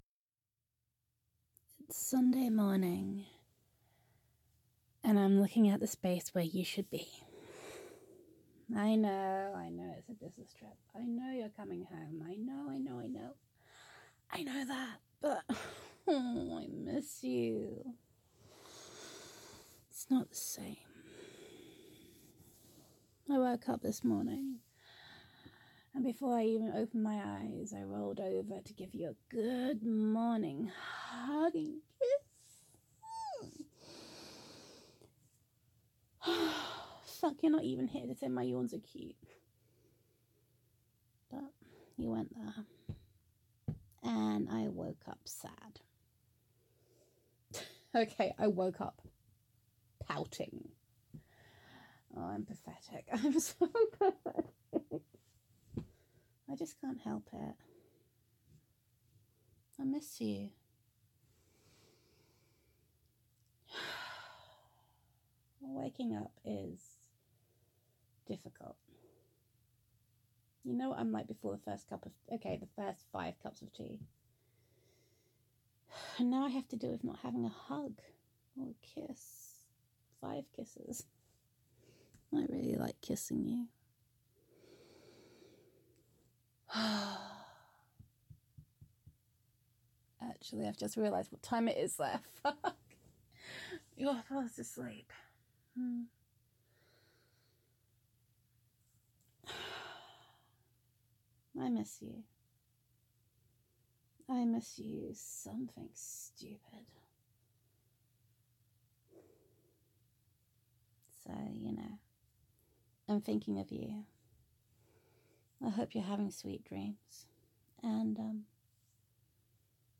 No fair throwing in happy squeaks!